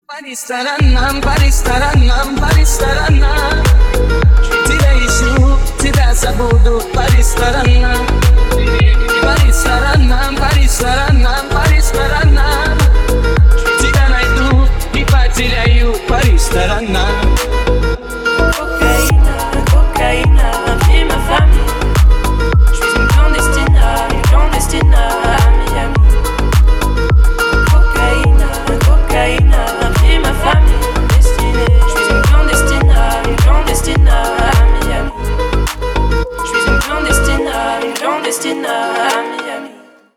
Гитара
Ремиксы
Deep house
Танцевальные